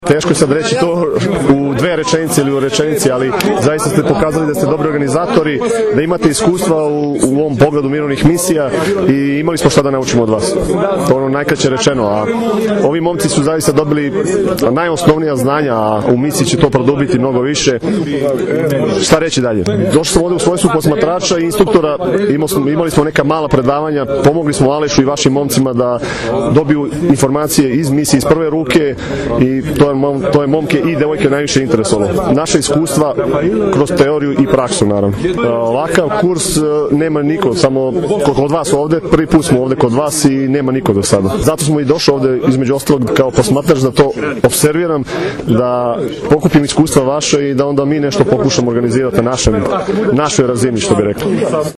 statement